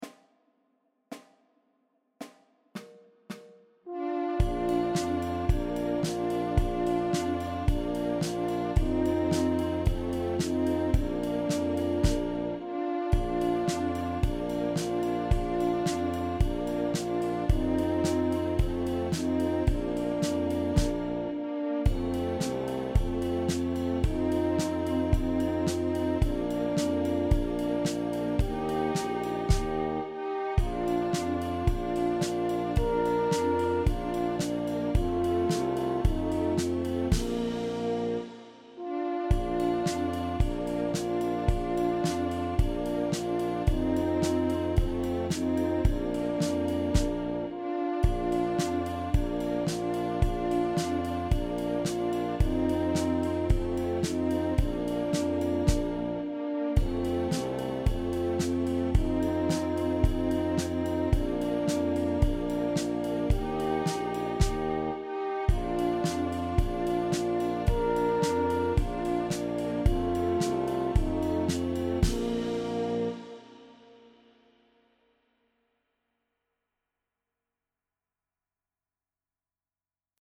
• 16 sehr leichte, dreistimmige Weihnachtslieder